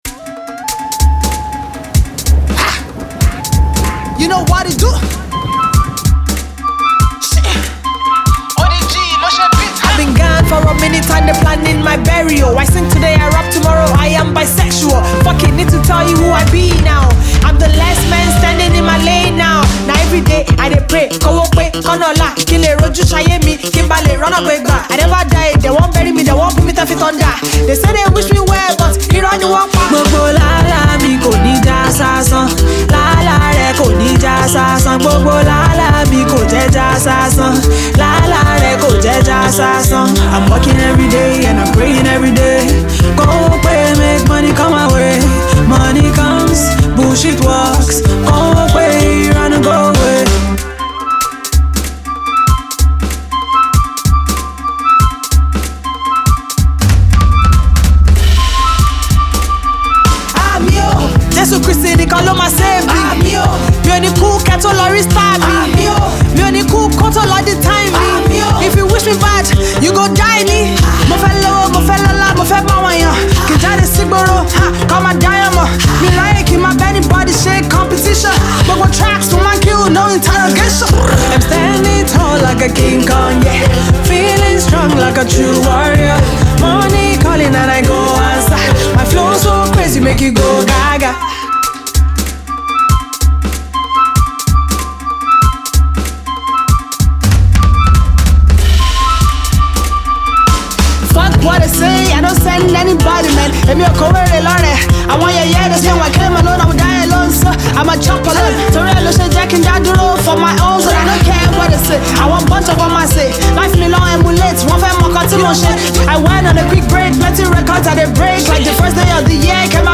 female rapper